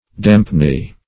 dampne - definition of dampne - synonyms, pronunciation, spelling from Free Dictionary Search Result for " dampne" : The Collaborative International Dictionary of English v.0.48: Dampne \Damp"ne\, v. t. To damn.
dampne.mp3